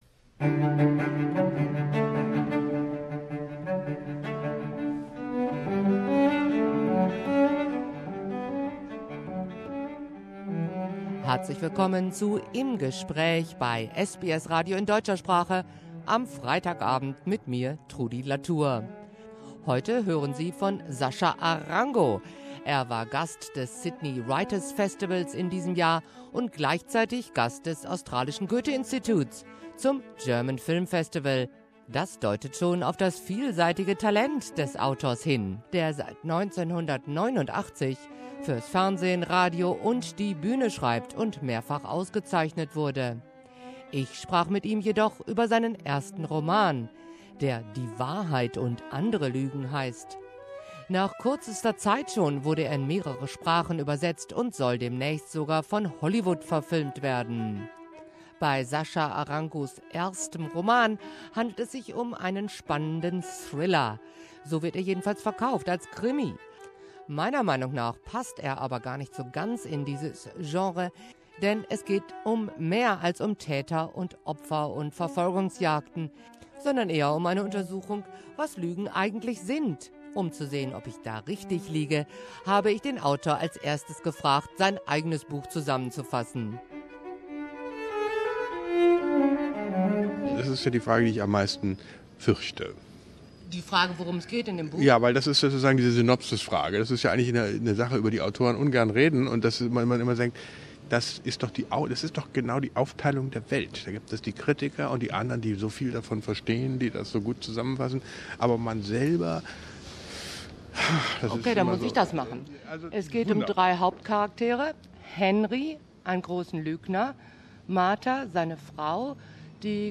Ich sprach mit ihm jedoch über seinen ersten Roman, Die Wahrheit und Andere Lügen.